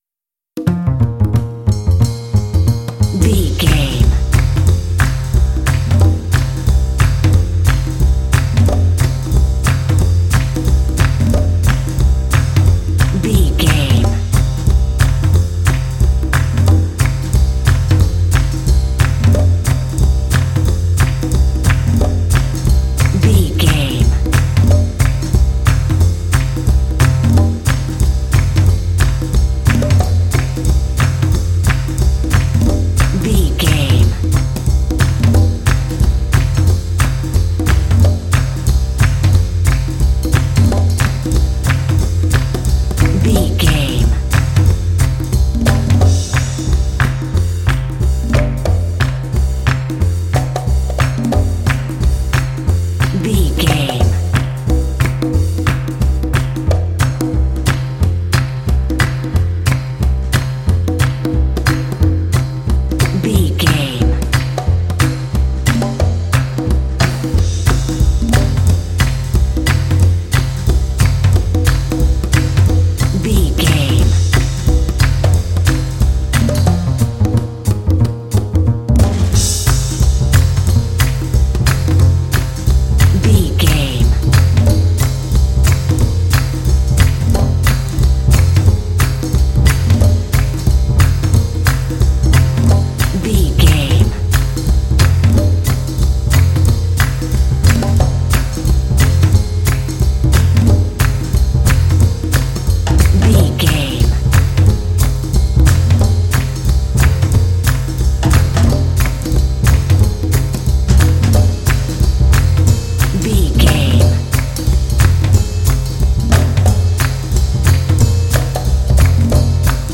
Uplifting
Aeolian/Minor
happy
bouncy
urban
drums
brass
percussion
bass guitar
saxophone